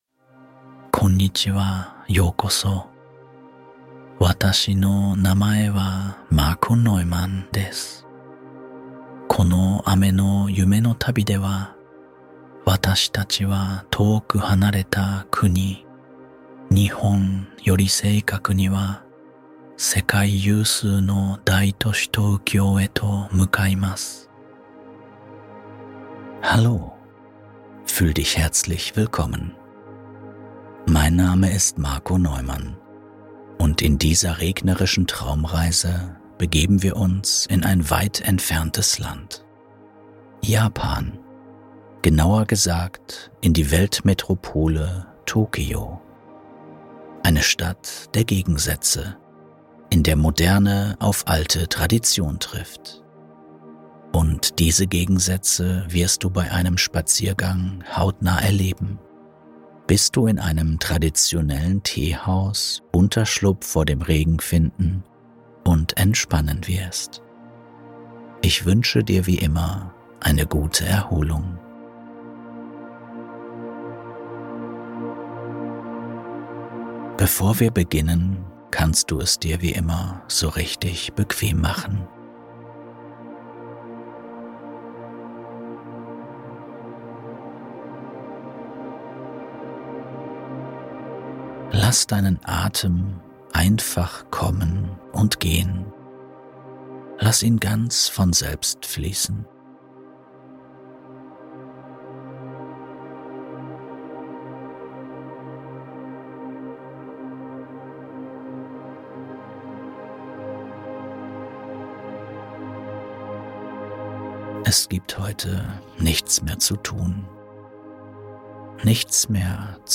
Tokyo bei Nacht: Die Traumreise, die MILLIONEN zum Einschlafen bringt + Regenklänge